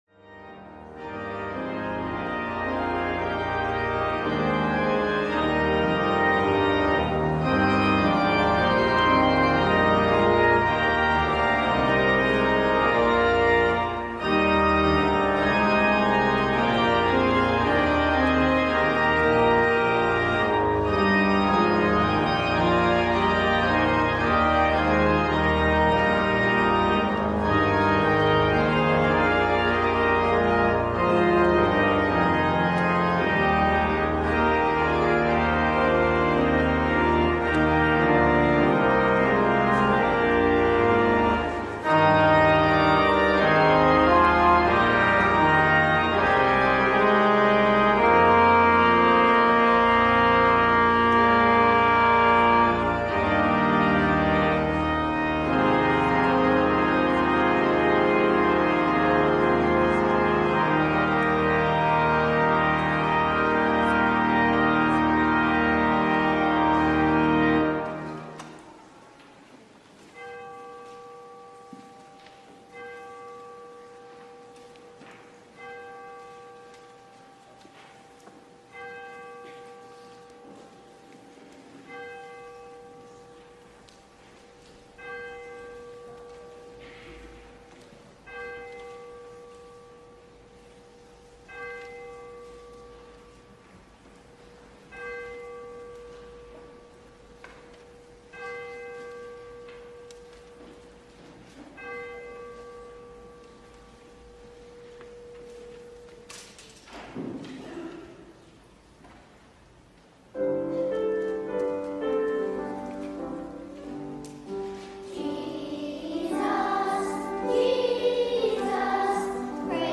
LIVE Morning Worship Service - Jubilee and Jesus
Congregational singing—of both traditional hymns and newer ones—is typically supported by our pipe organ.